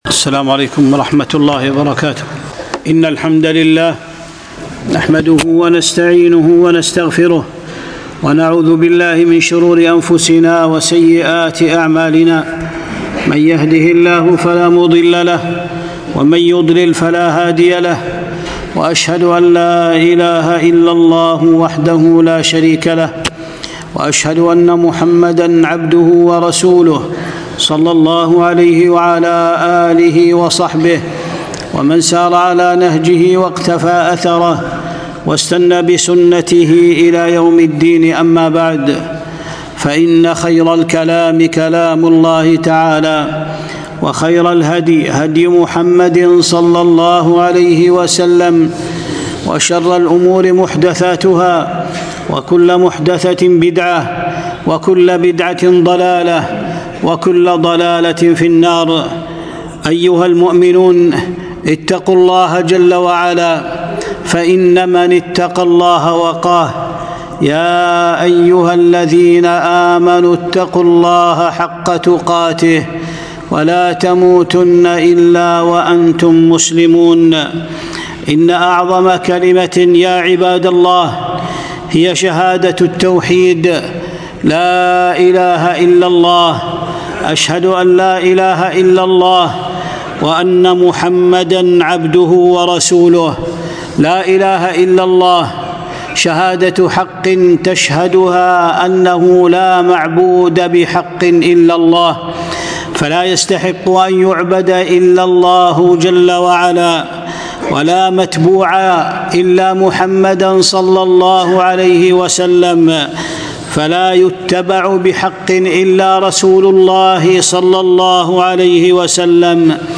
خطبة - الشهادتين ٢٦-رجب-١٤٤٤-هـ (الكويت)